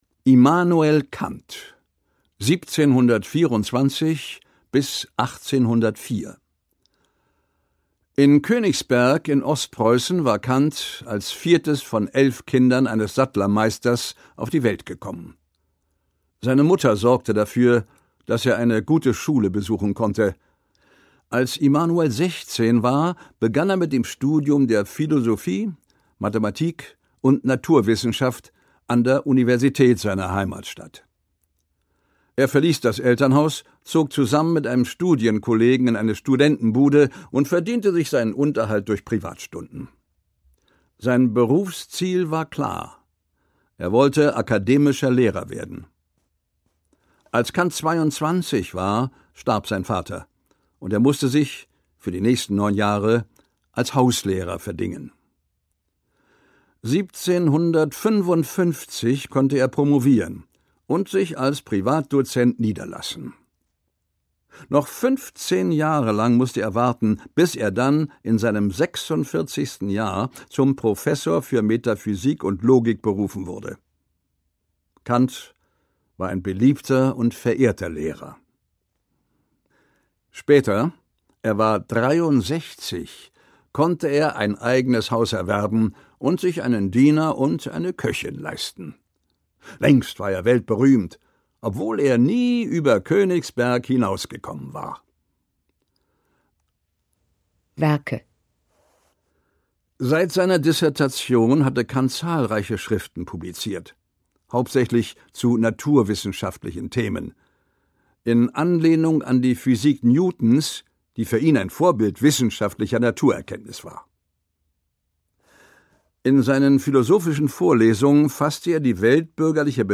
2008 | Hörbuch